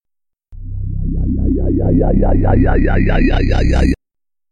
Electronic Dj Transition Sound Effect Free Download
Electronic Dj Transition